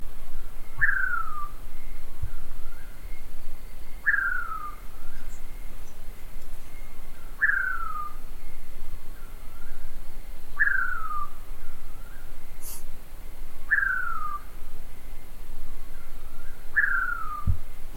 Atajacaminos Ocelado (Nyctiphrynus ocellatus)
De fondo se escucha Atajacaminos Oscuro
Condición: Silvestre
Certeza: Observada, Vocalización Grabada
Atajacaminos-Ocelado-y-Oscuro-Cruce.mp3